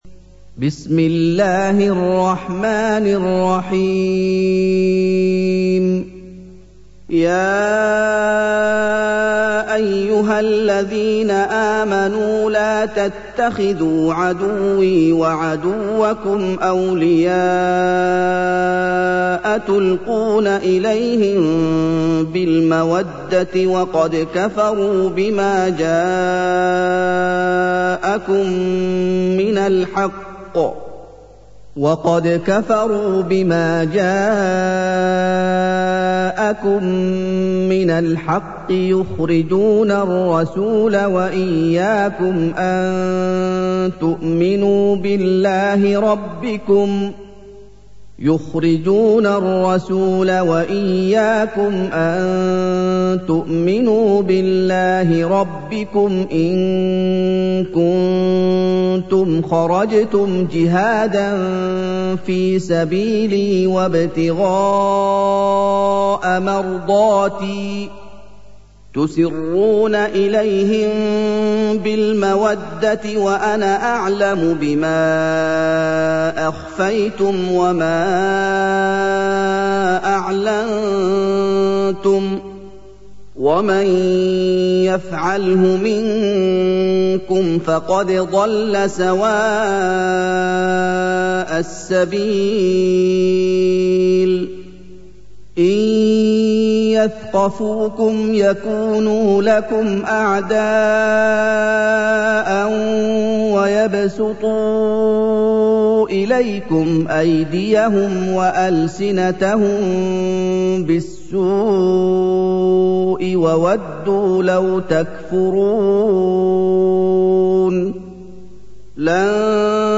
سُورَةُ المُمۡتَحنَةِ بصوت الشيخ محمد ايوب